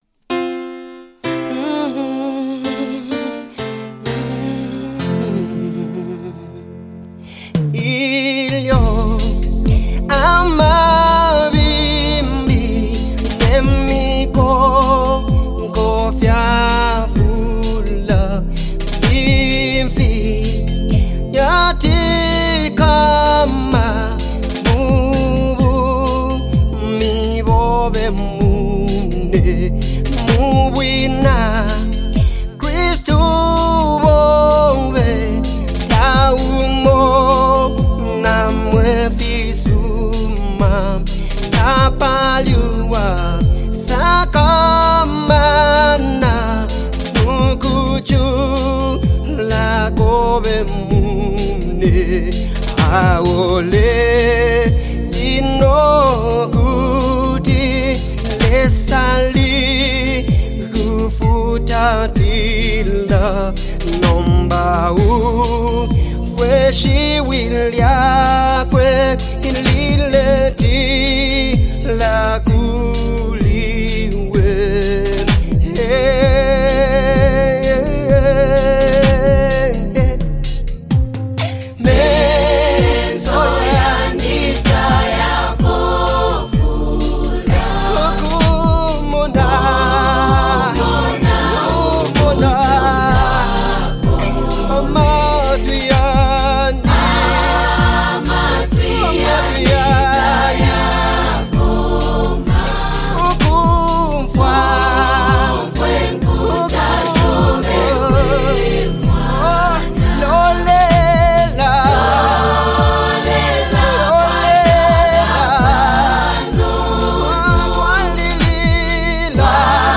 With its soothing melody and rich vocals